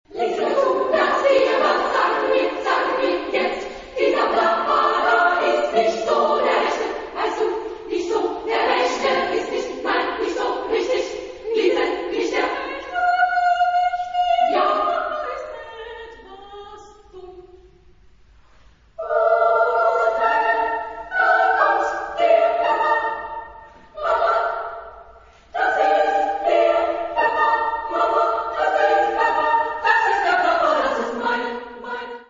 Género/Estilo/Forma: ciclo ; Profano ; contemporáneo
Tonalidad : atonal